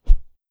Swing On Air
Close Combat Swing Sound 5.wav